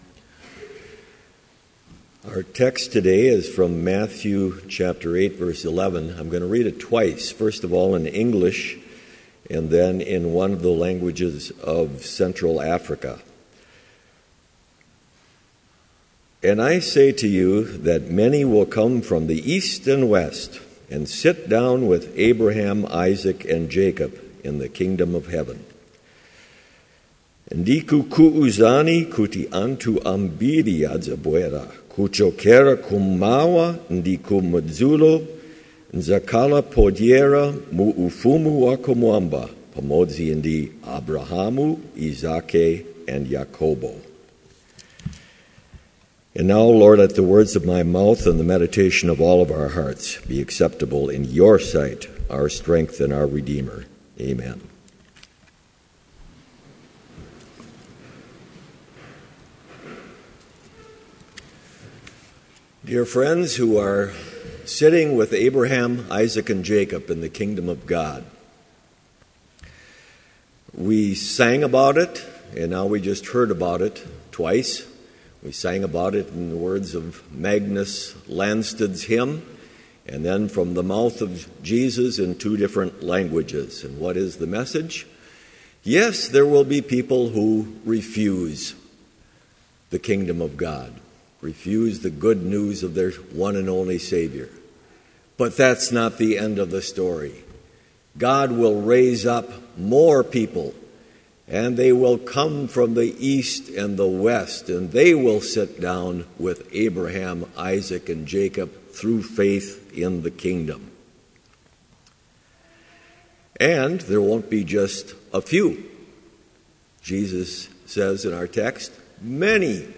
Complete service audio for Chapel - January 29, 2015